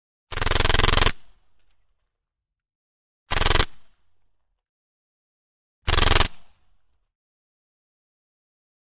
Mac-10|Silenced
9 mm Mac-10 Automatic ( Silenced ): Multiple Bursts; Three Short, Silenced Bursts Of Automatic Fire. Very Rapid Low End Sounding Shots With Shells Falling After Bursts. Very Little Echo. Close Up Perspective. Gunshots.